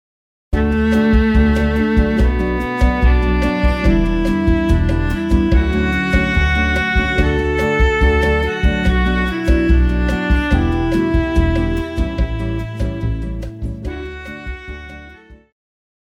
Pop
Viola
Band
Traditional (Folk),Movie/TV
Instrumental
Ballad
Solo with accompaniment